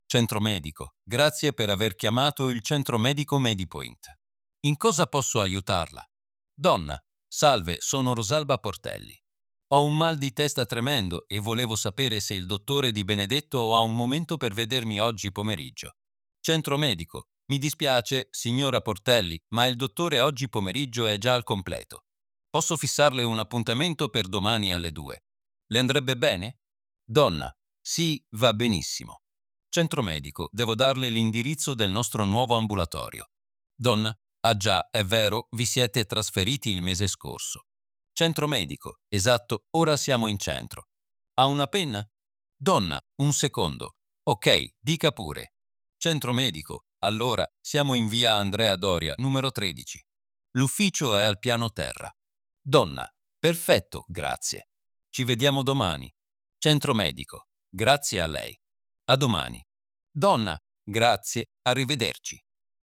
hasznos párbeszédek audióval
HASZNOS PÁRBESZÉD: Időpontfoglalás telefonon